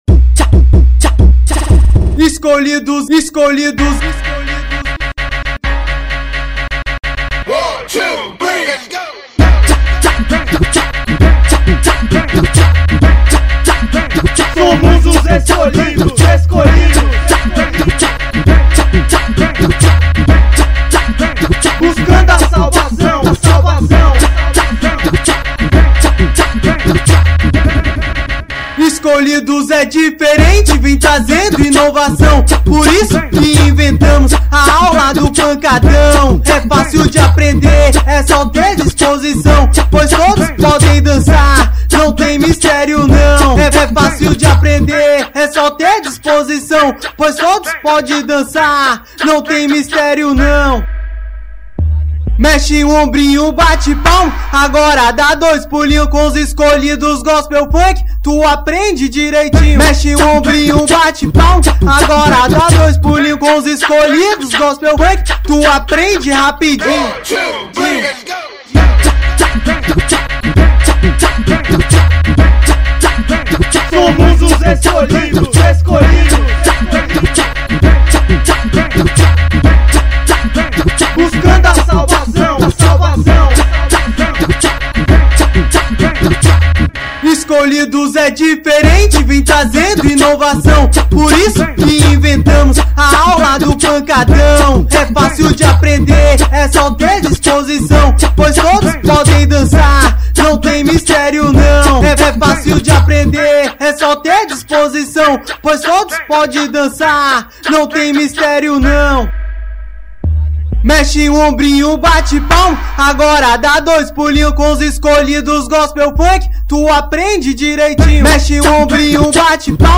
é uma música